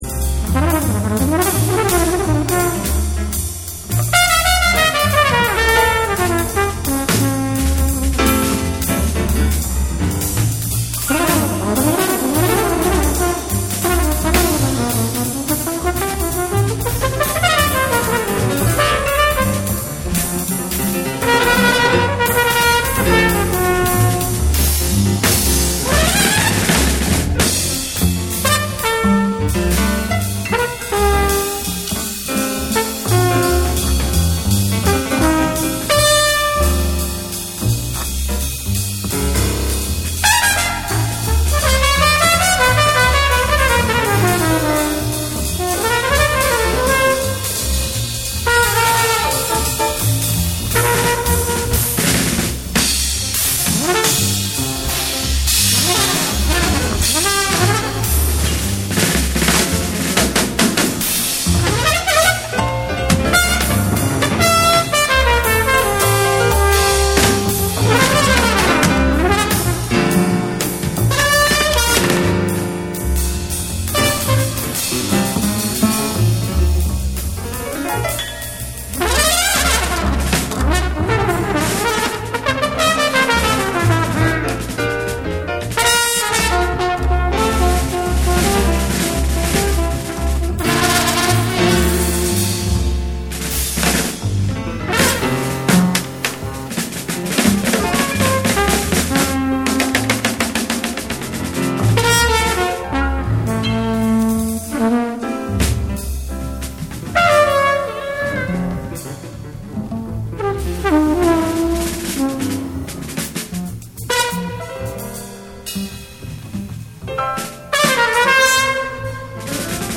SOUL & FUNK & JAZZ & etc